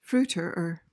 PRONUNCIATION:
(FROO-tuhr-uhr)